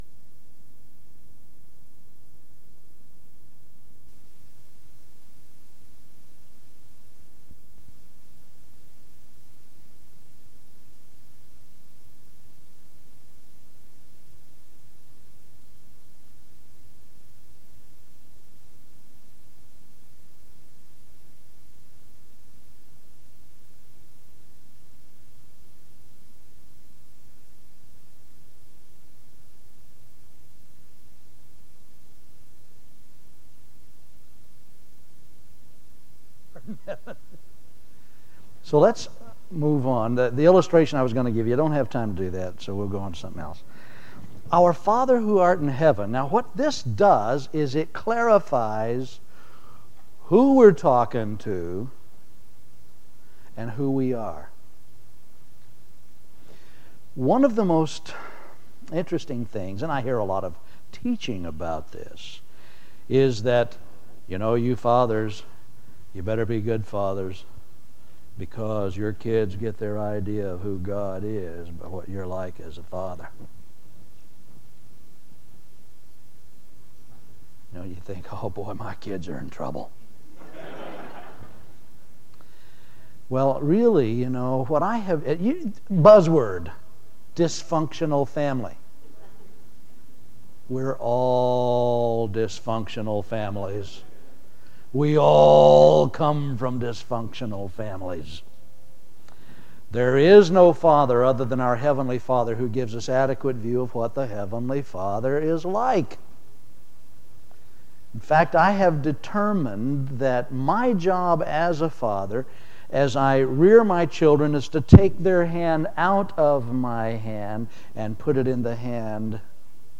Home » Sermons » 1998 DSPC: Session 8